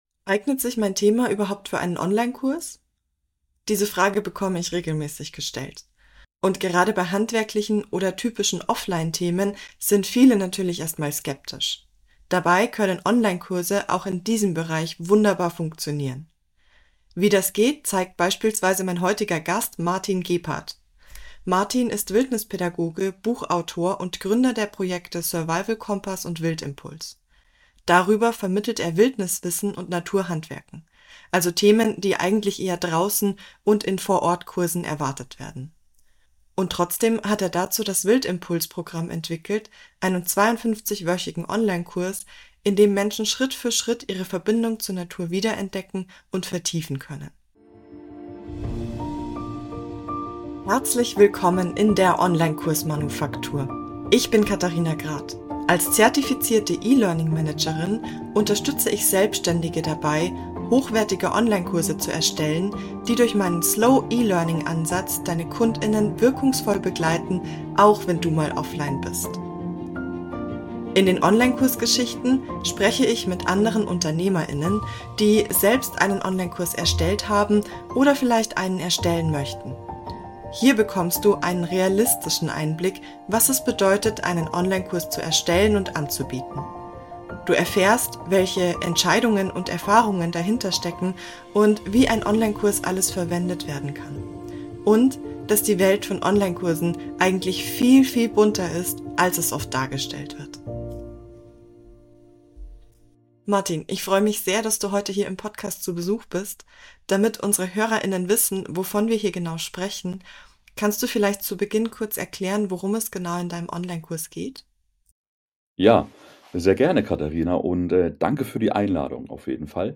Du möchtest mir auch ein Interview geben?